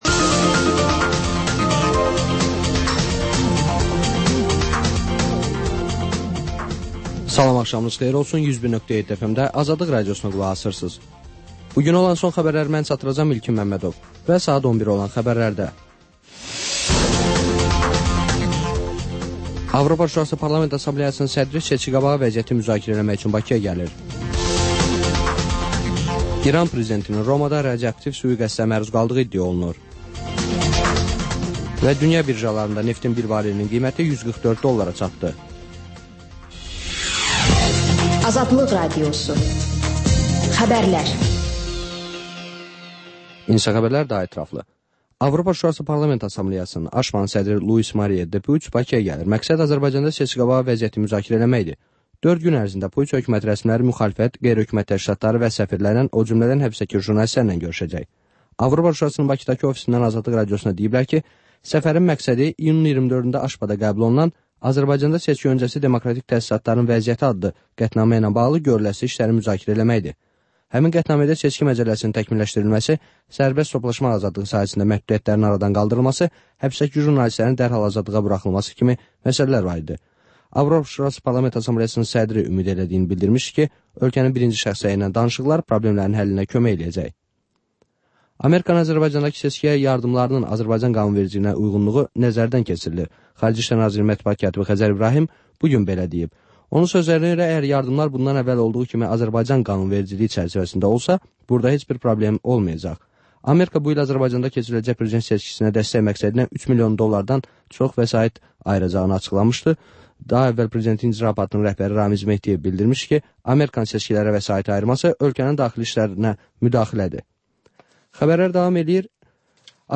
Xəbərlər, müsahibələr, hadisələrin müzakirəsi, təhlillər, sonda isə XÜSUSİ REPORTAJ rubrikası: Ölkənin ictimai-siyasi həyatına dair müxbir araşdırmaları